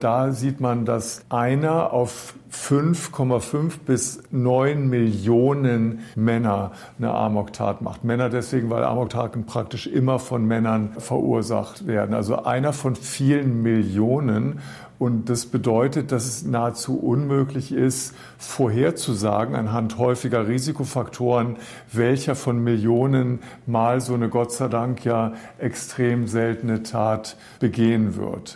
sagte im SWR-Interview